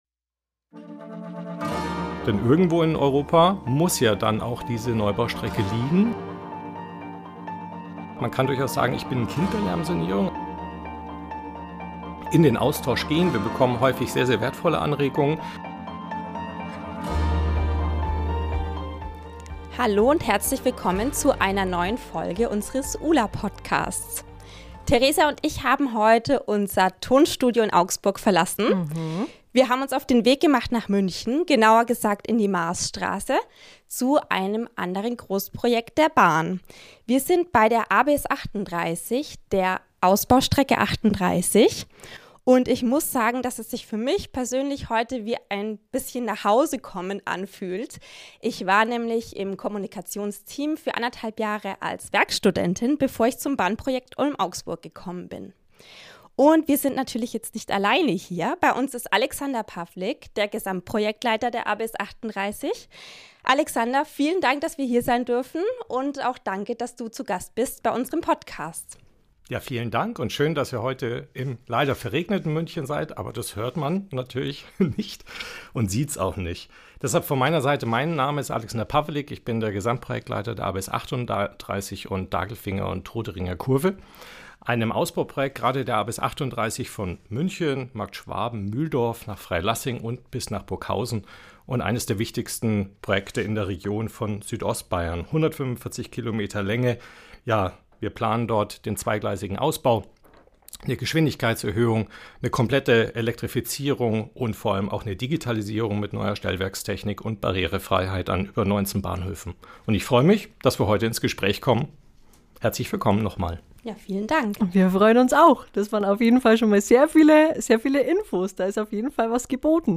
Mit ihm haben wir über Schallschutz, europäische Verkehre und turbulente Veranstaltungen gesprochen.